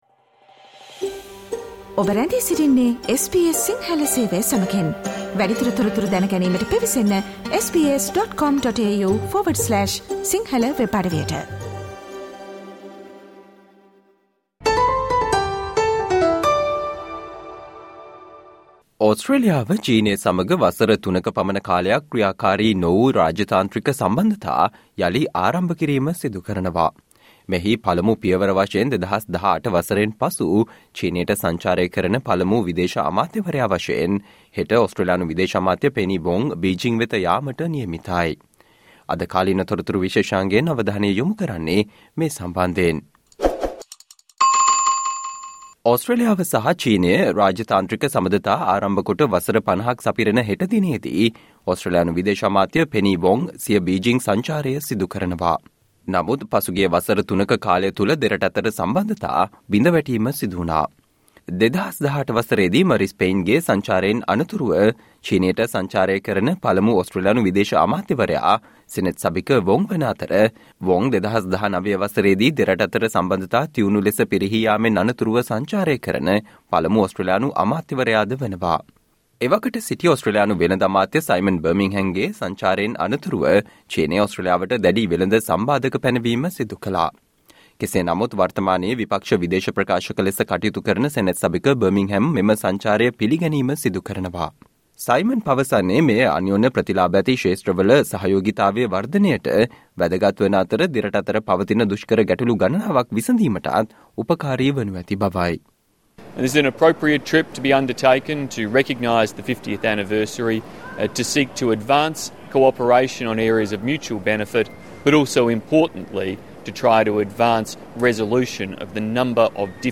Today -20 December, SBS Sinhala Radio current Affair Feature on First step by Australian Labor government to rebuild China-Australia relations